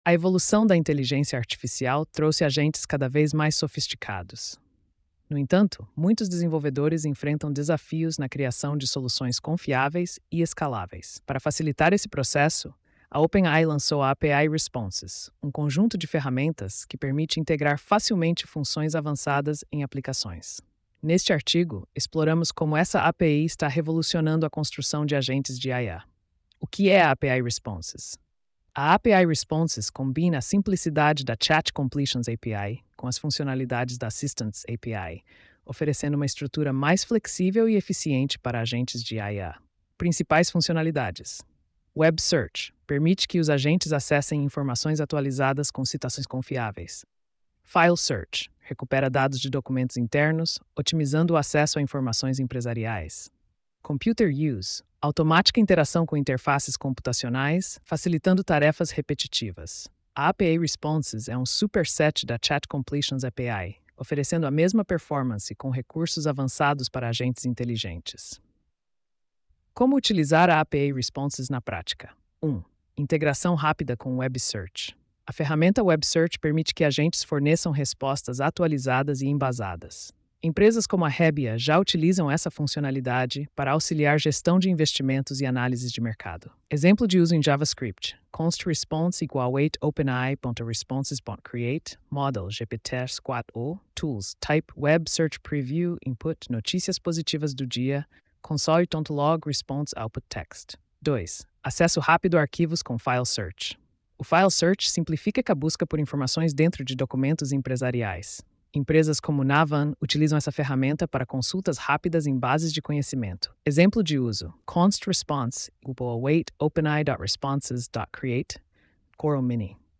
post-2859-tts.mp3